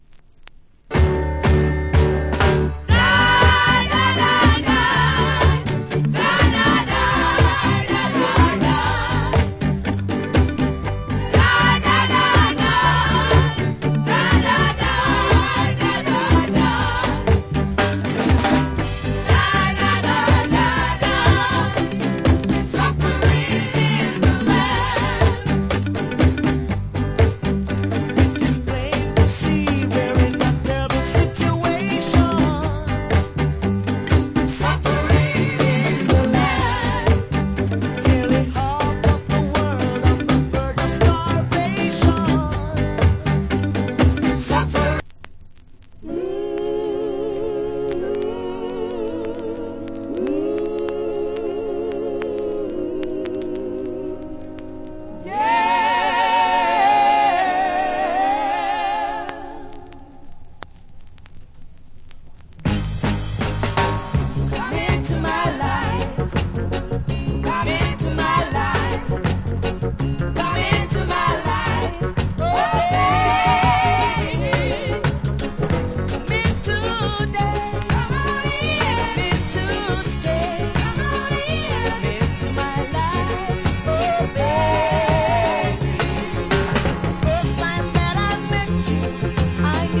Great Early Reggae.